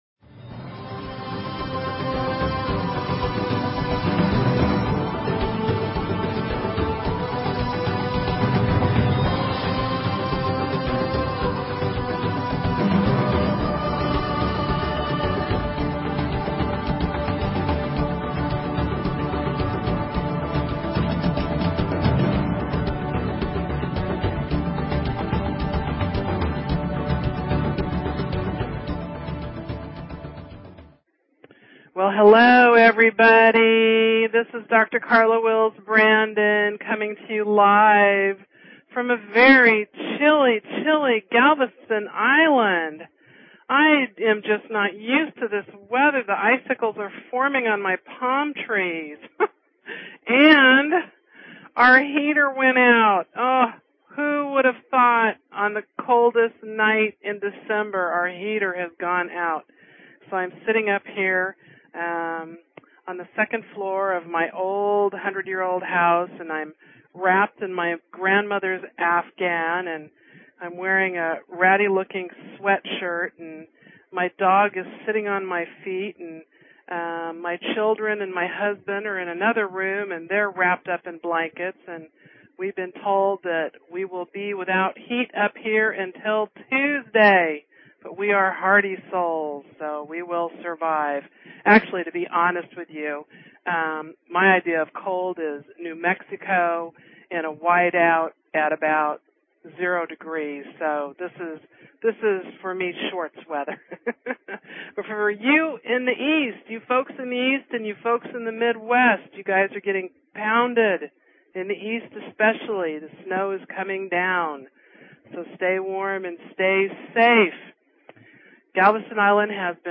Talk Show Episode, Audio Podcast, A_Walk_To_Otherside and Courtesy of BBS Radio on , show guests , about , categorized as